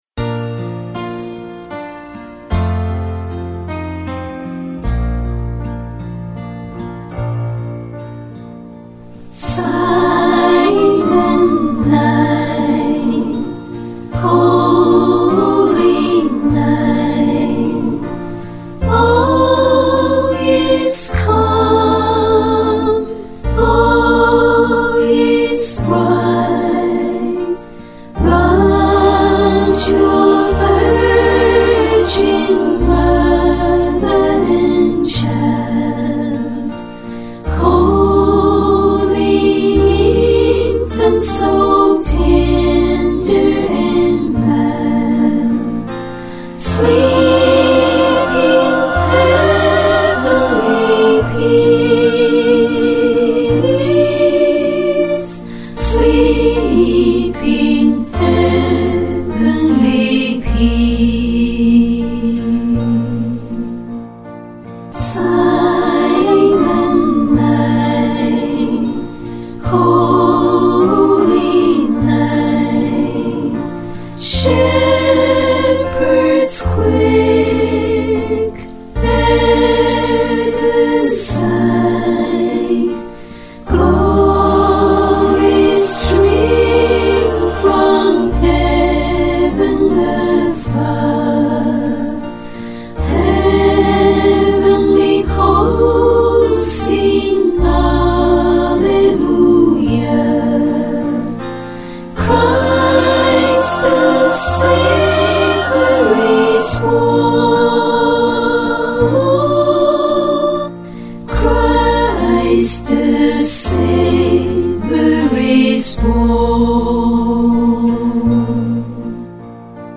Silent Night 三重唱
多麼美妙迷人的和聲！
好聽極了，非常棒的和聲，賽過在大教堂里聽百人合唱。